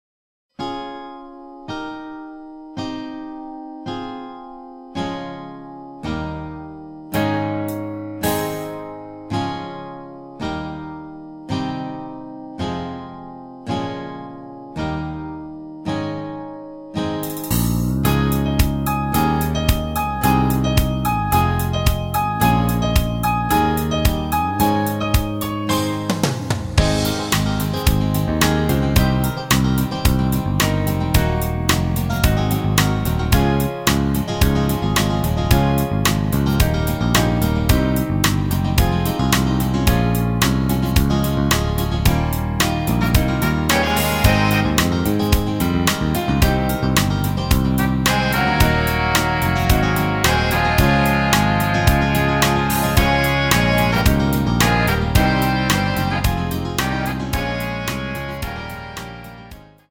전주 없이 노래 들어가는 곡이라 전주 만들어 놓았습니다. 8초쯤 하이햇 소리 끝나고노래 시작 하시면 됩니다.